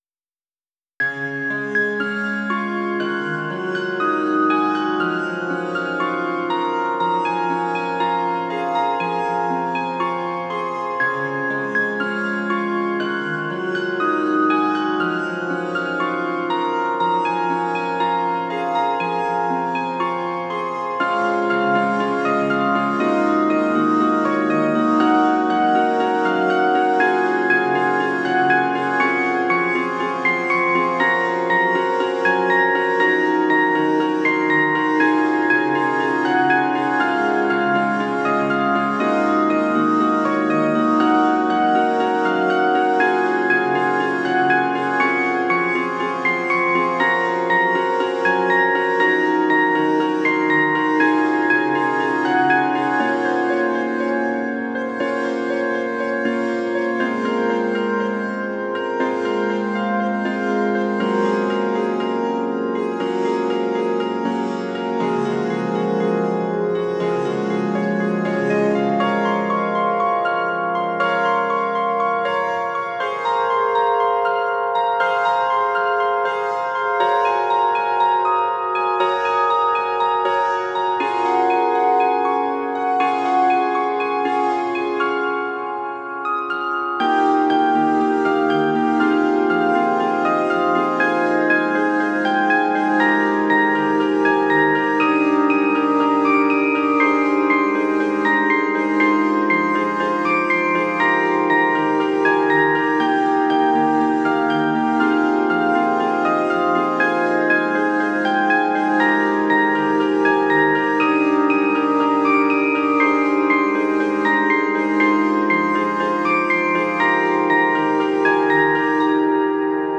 PIANO H-P (34)